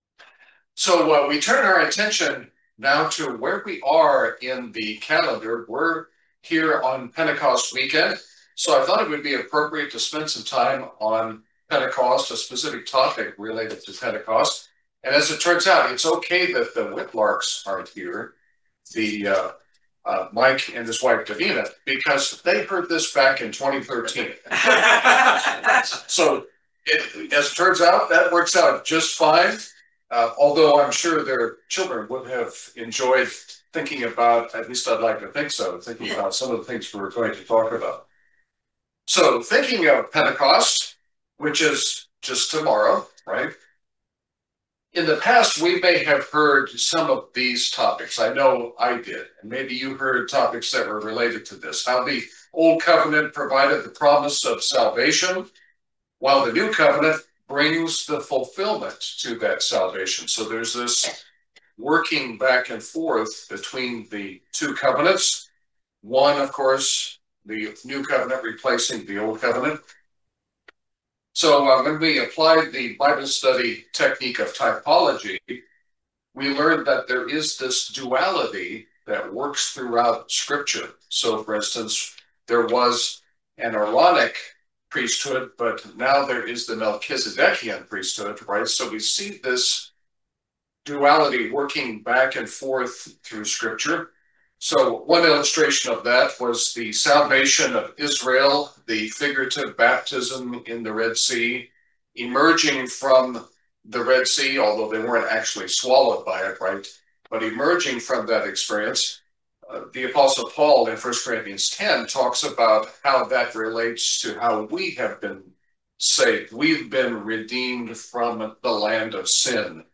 This is a very eye opening video sermon on the Two Wave Loaves.
Given in Lexington, KY